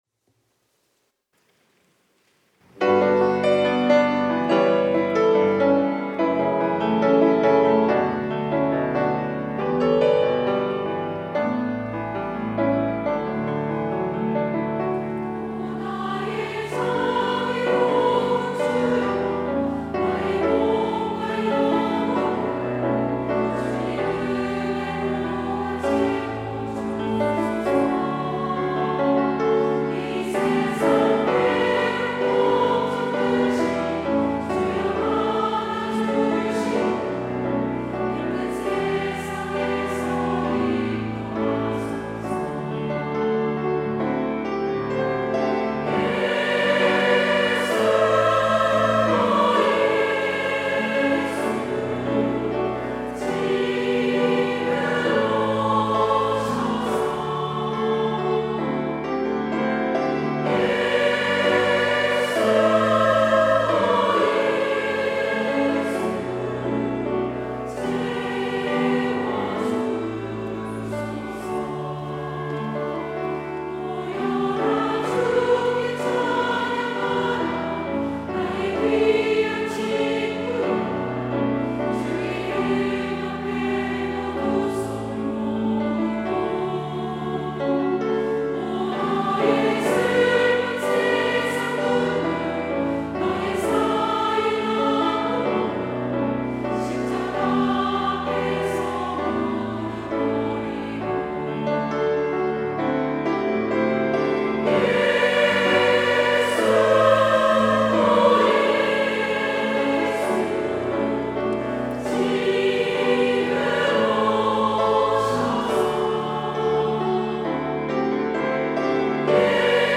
찬양대 여전도회